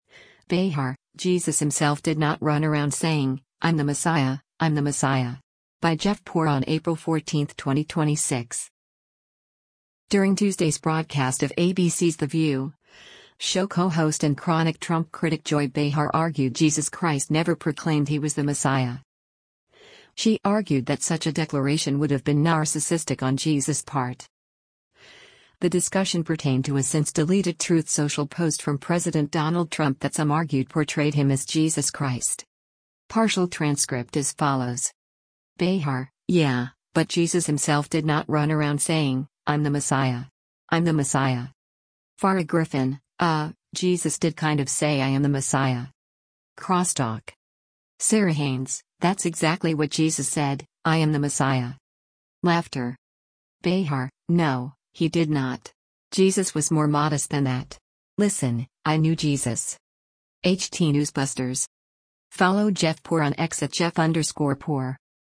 During Tuesday’s broadcast of ABC’s “The View,” show co-host and chronic Trump critic Joy Behar argued Jesus Christ never proclaimed he was the Messiah.